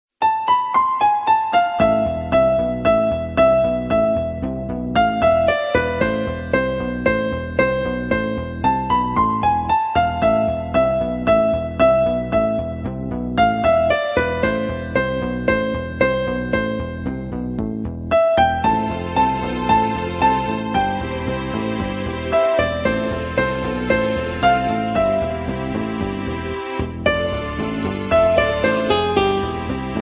Klasyczny